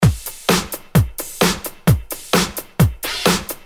Free MP3 electro drumloops soundbank 2
Electro rythm - 130bpm 21